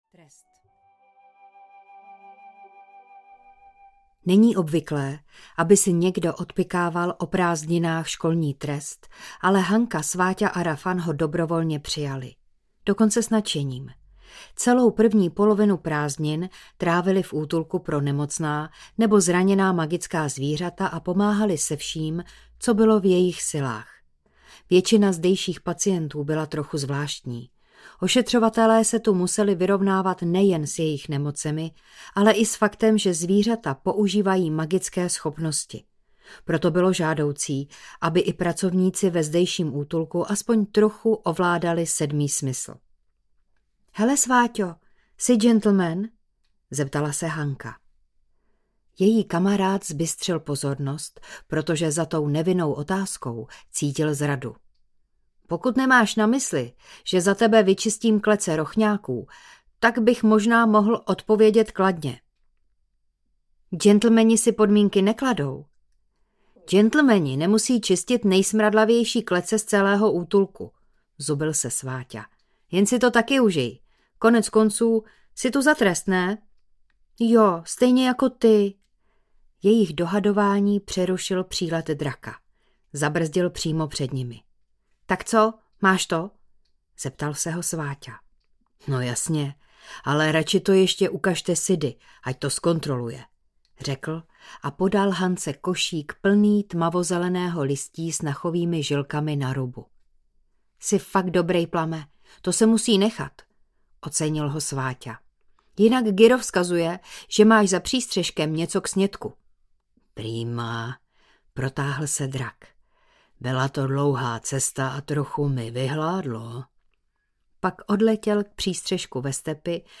Smrt kouzelného džina audiokniha
Ukázka z knihy